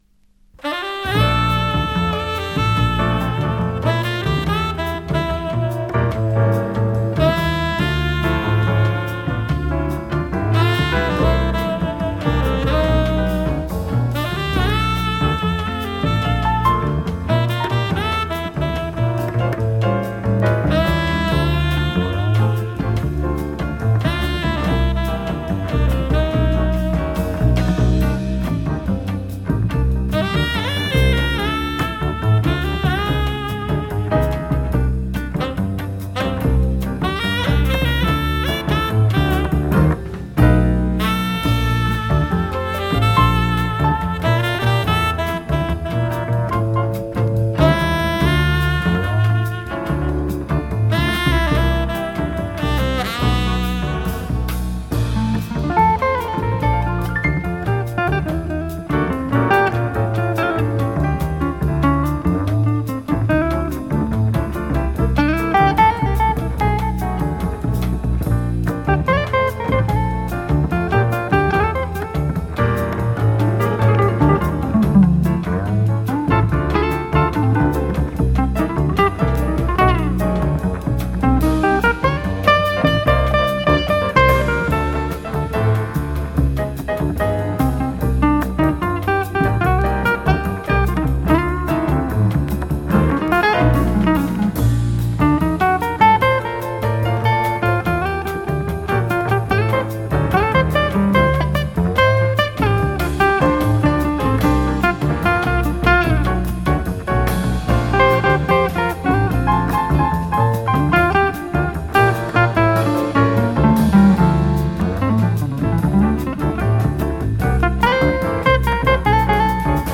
サックス2管、ギター、ピアノ、ベース、ドラムが基本の編成です。
憂いと清涼感が同居する